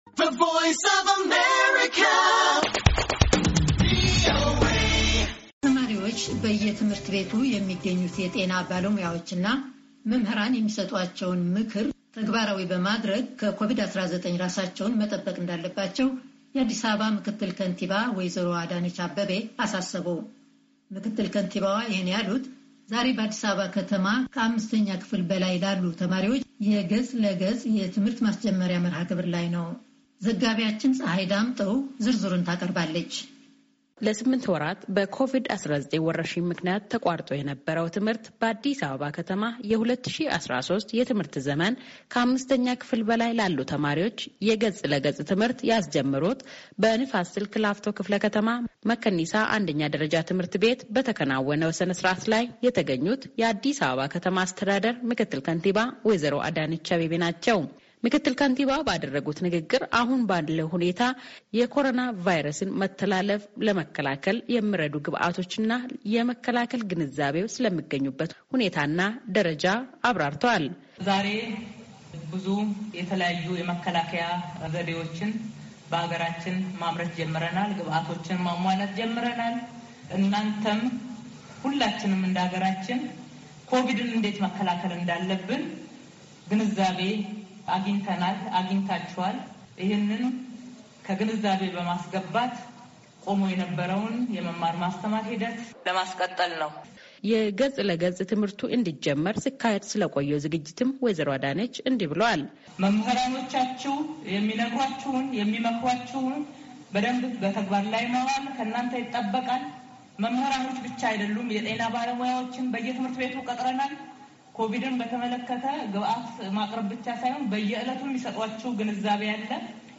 ምክትል ከንቲባዋ ይህን ያሉት ዛሬ በአዲስ አበባ ከተማ ከአምስተኛ ክፍል በላይላሉ ተማሪዎች የገፅ ለገፅ የትምህርት ማስጀመሪያ መረሃ ግብር ላይ ነው።